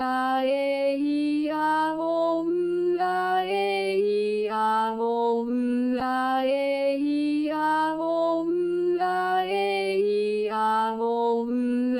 母音音声の無限音階化
endless_up_aeiaou.wav